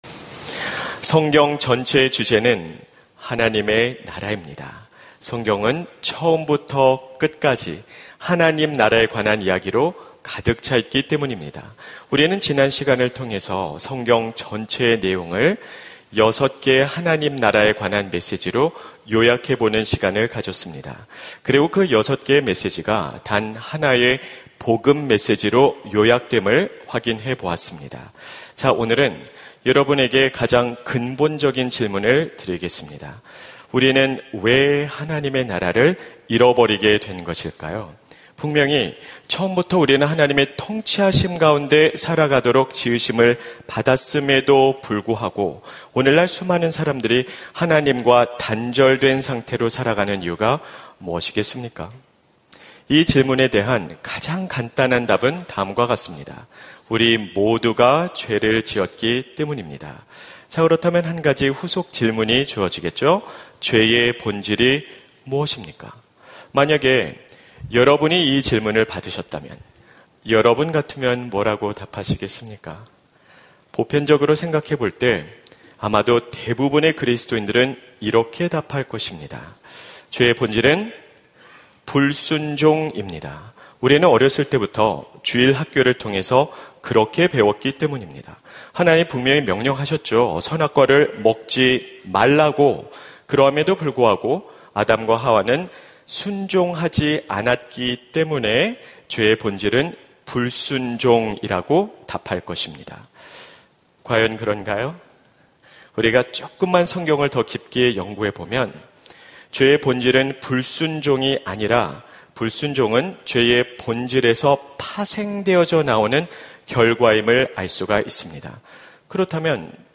설교 : 주일예배 복음수업 - 복음수업 5 : 하나님은 왜 선악과를 만드셨나요? 설교본문 : 창세기 2:15-17